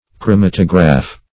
Meaning of chromatograph. chromatograph synonyms, pronunciation, spelling and more from Free Dictionary.
Search Result for " chromatograph" : The Collaborative International Dictionary of English v.0.48: chromatograph \chro*mat"o*graph`\ n. a piece of equipment used to perform chromatography[2].